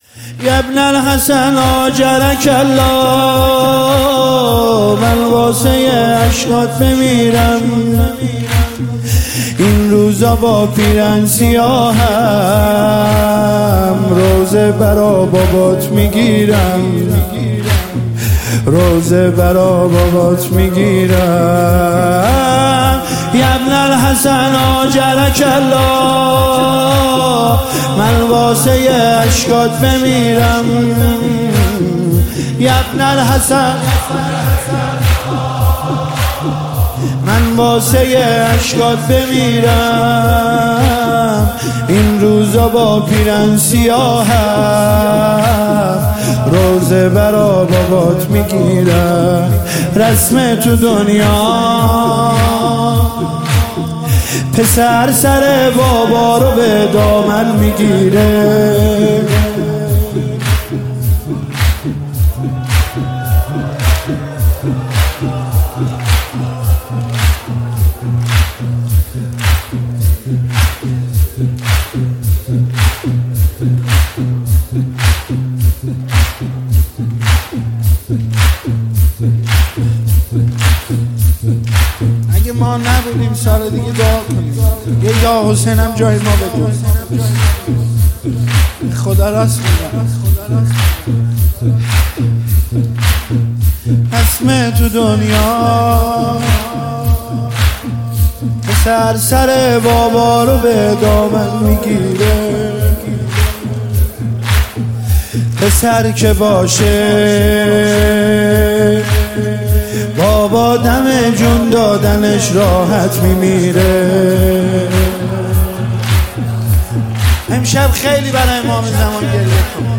مداحی شهادت امام حسن عسکری (ع)